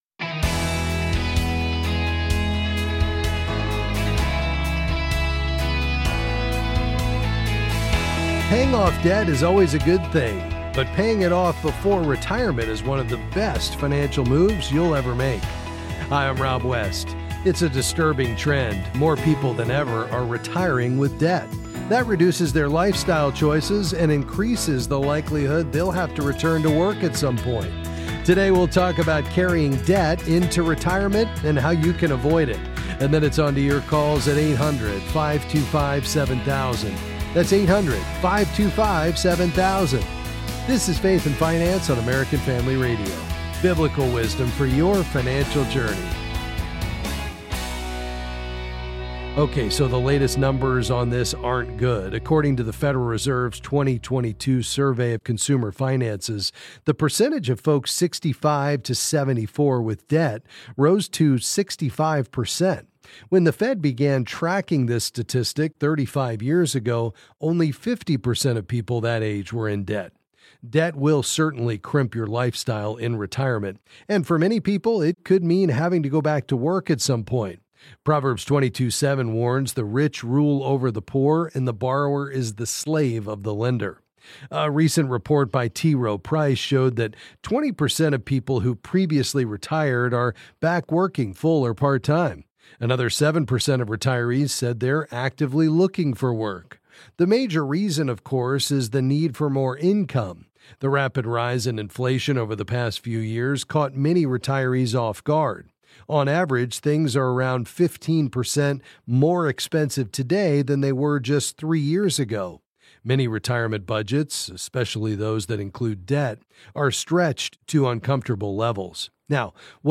Then he answers questions on various financial topics.